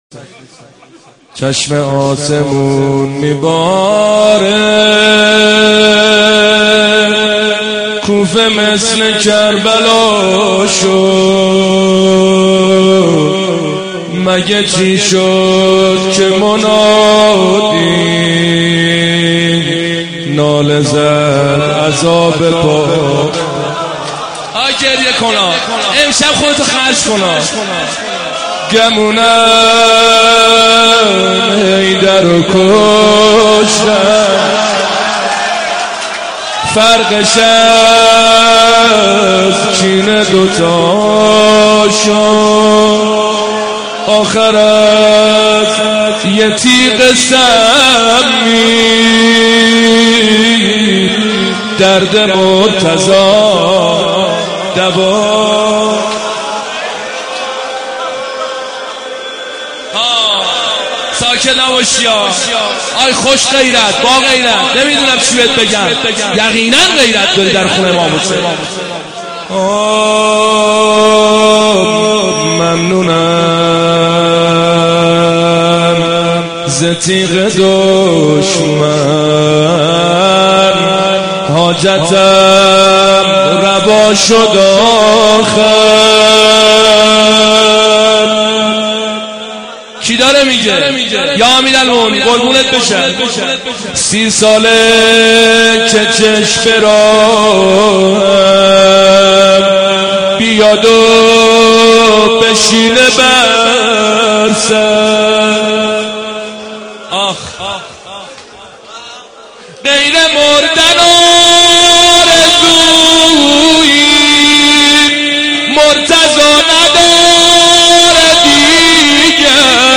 رمضان 89 - روضه
رمضان-89---روضه